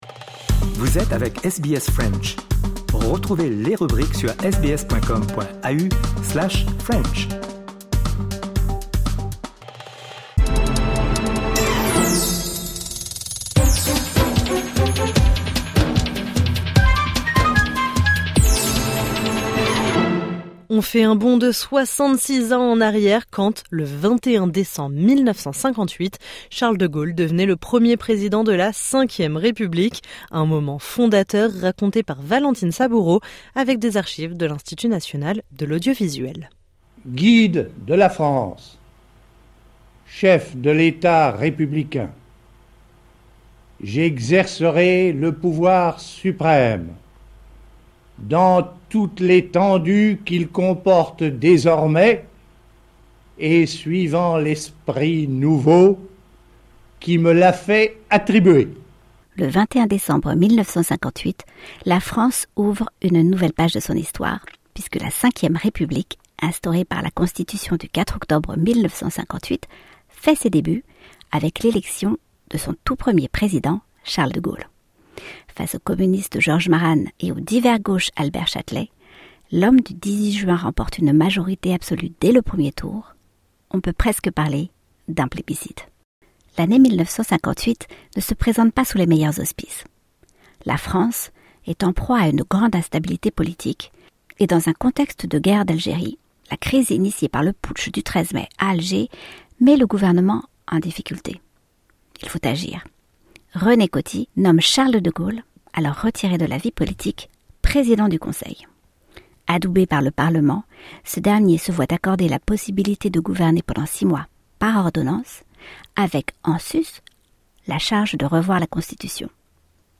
Un moment fondateur, raconté ici avec les archives de l’Institut national de l’audiovisuel.